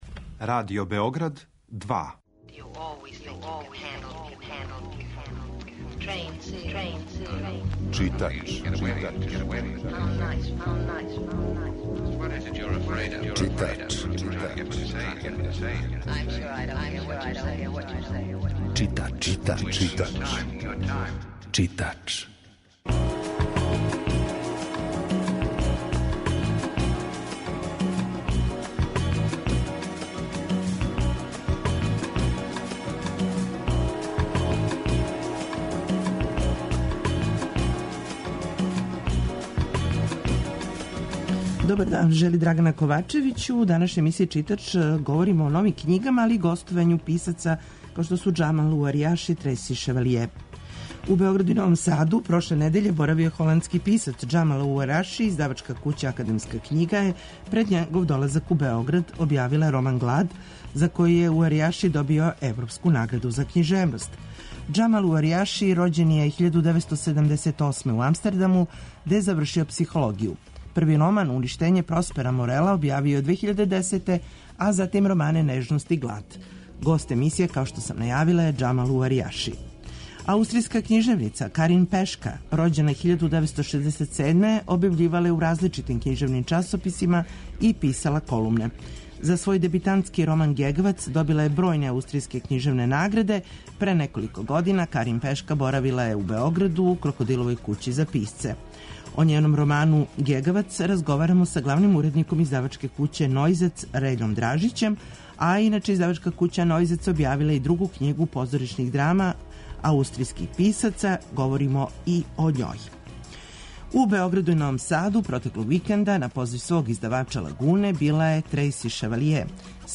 Чућете разговор са Трејси Шевалије.
Емисија је колажног типа, али је њена основна концепција – прича о светској књижевности